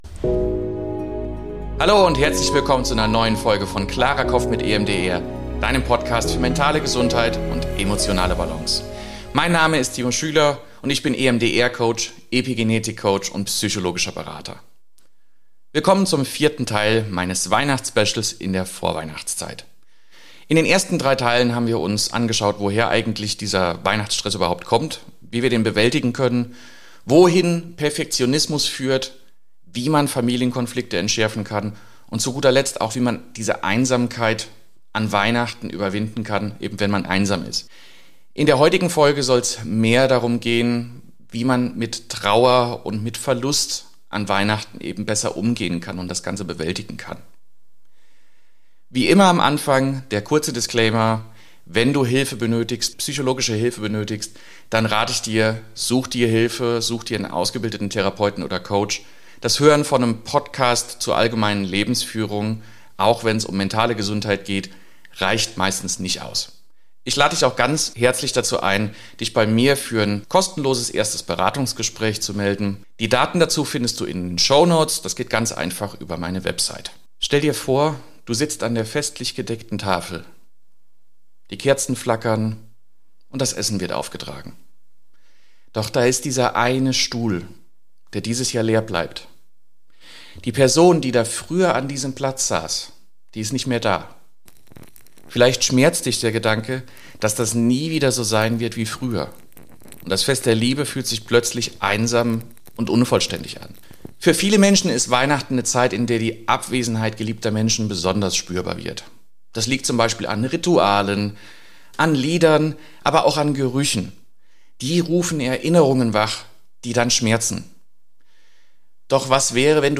Zusätzlich führe ich dich durch eine liebevolle Meditation – die „Kerze der Erinnerung“ –, die dir hilft, Raum für deine Gefühle zu schaffen und gleichzeitig die Verbindung zu bewahren.